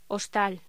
Locución: Hostal